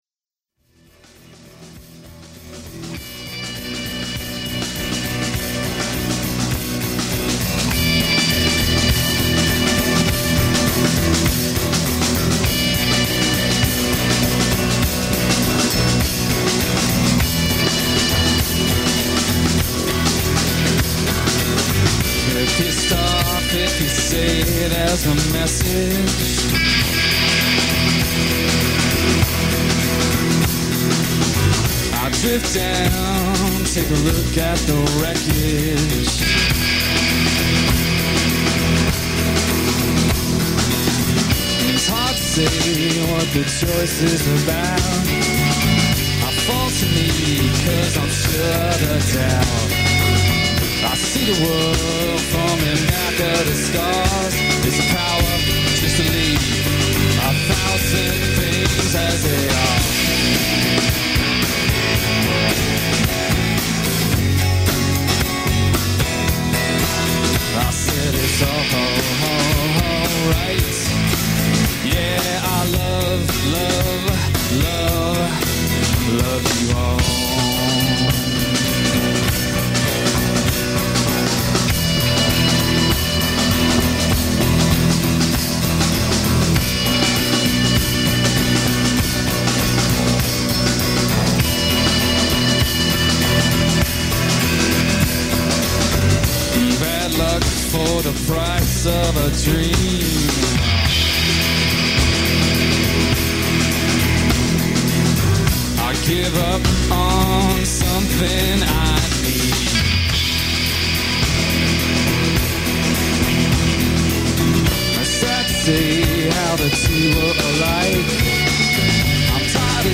Live Festival de Vernier sur Rock – 1991